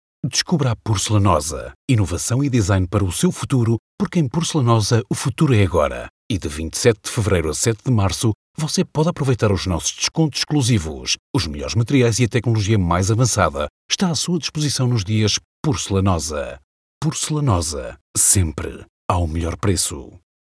Naturelle, Enjouée, Amicale, Chaude, Corporative
Commercial